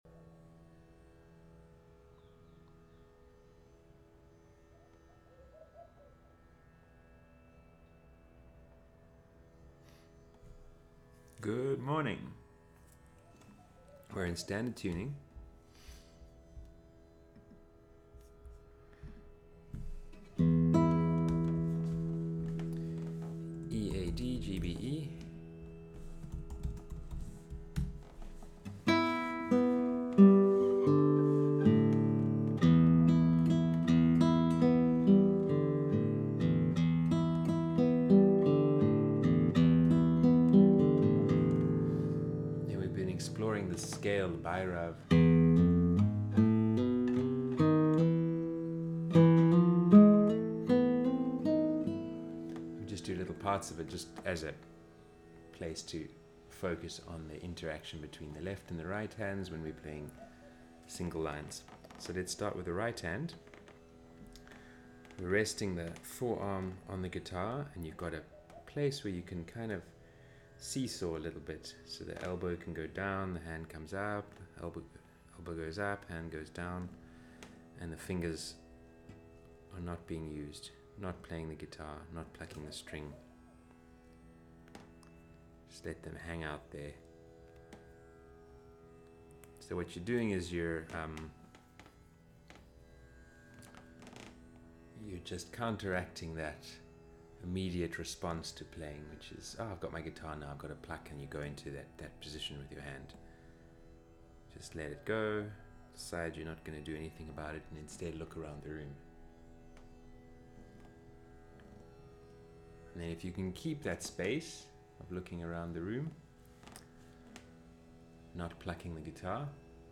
Video lessons uploaded after every online group class.